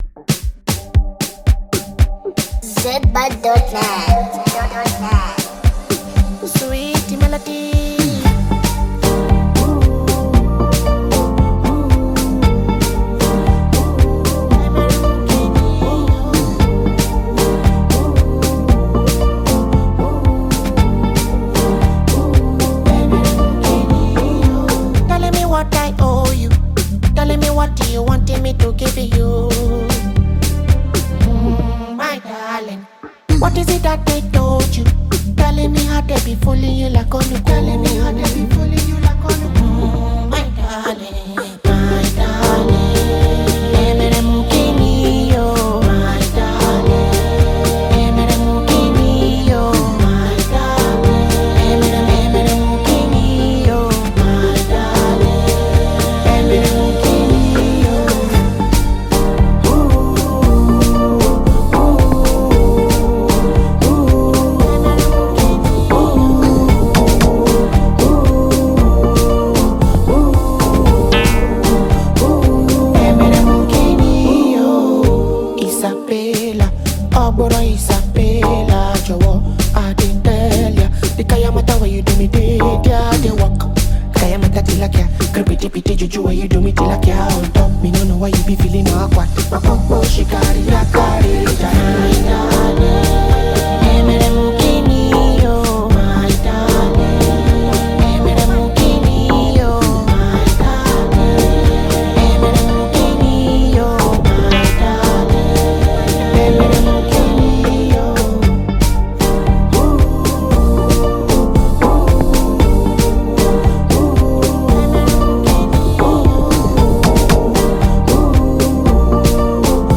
Genre: Naija Music